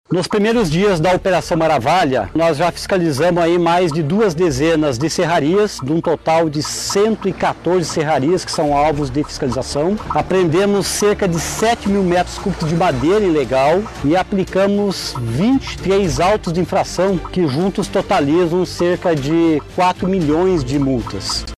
O diretor de Proteção Ambiental do Ibama, Jair Schmitt, detalha os resultados dessa primeira fase da Operação Maravalha.